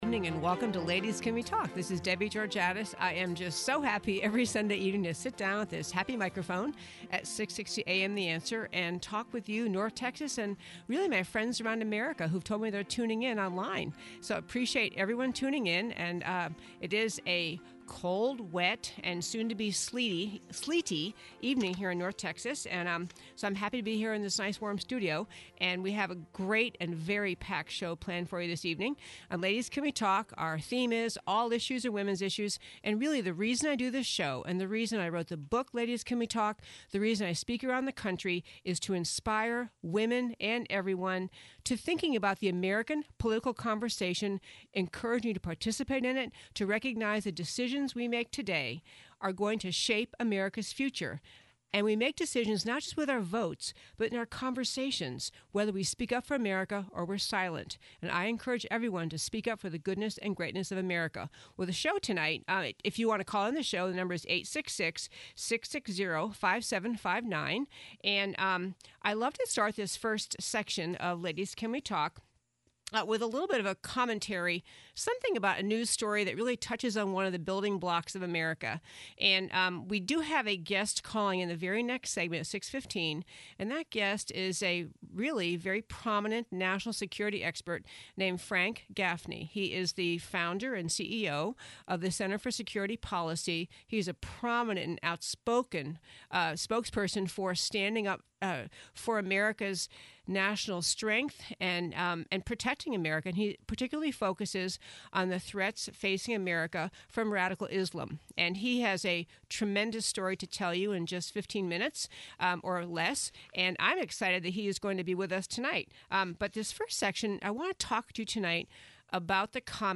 Was Giuliani right? Conversation with Frank Gaffney, Tribute to Black History Month
Listen to the podcast from the first hour of our February 22nd show on 660AM.